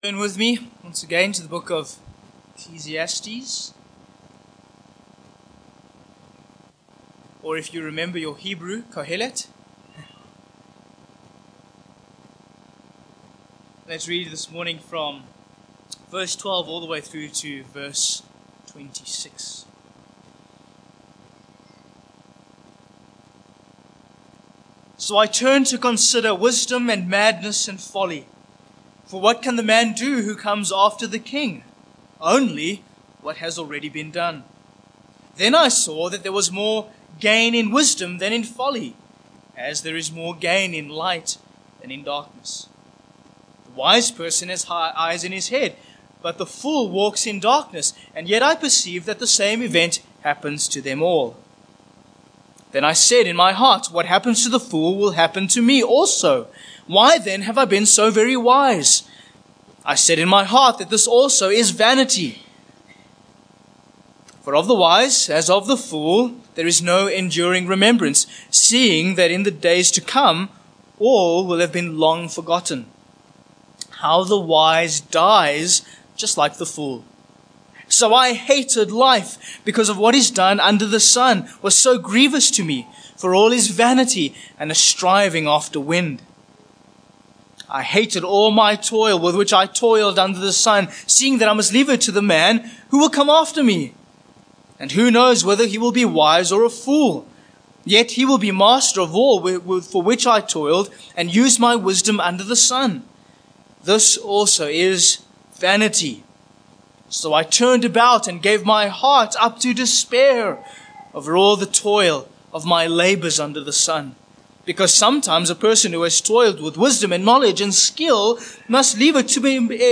Ecclesiastes 2:24-26 Service Type: Morning Passage